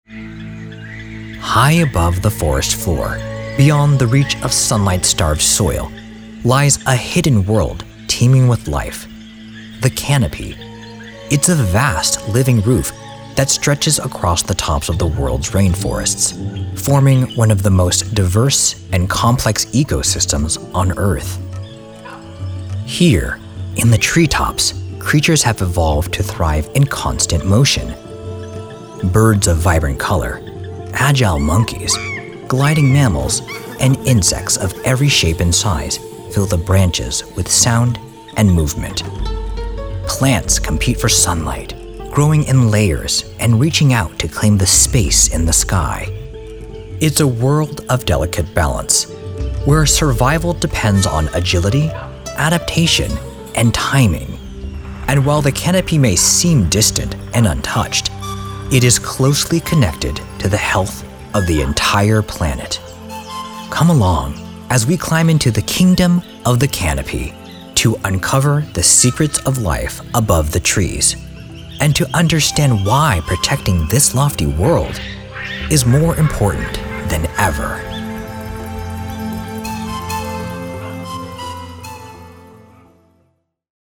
Pro Sounding Luxury, High End, Calm Voice
Narration Demo
Narration: Strong, Intelligent, Knowledgable, Deep, Sophistication, Well-Paced, Grounded, Calm, Assured, Luxury, Believable, Persuasive, Serious, Seductive, Smooth, Genuine, Relatable, Wisdom and Experienced.